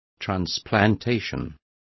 Complete with pronunciation of the translation of transplantations.